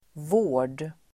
Uttal: [vå:r_d]